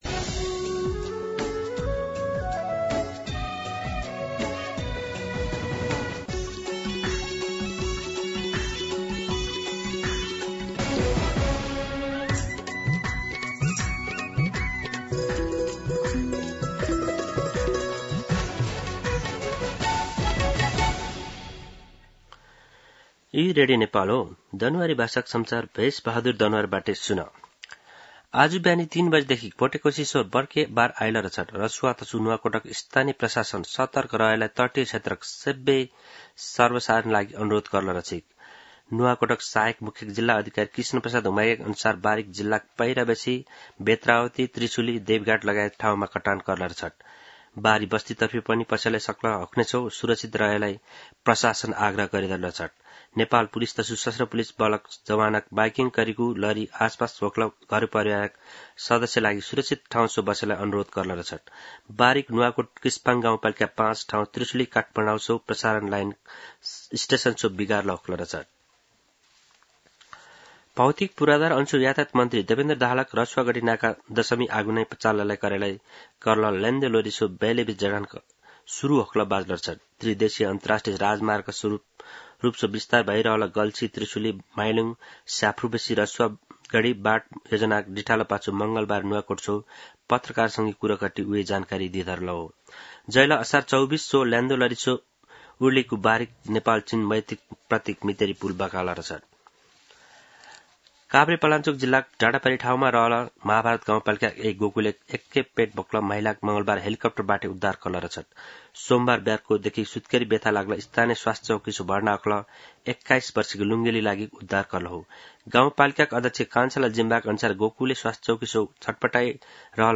दनुवार भाषामा समाचार : १४ साउन , २०८२
Danuwar-News-04-14.mp3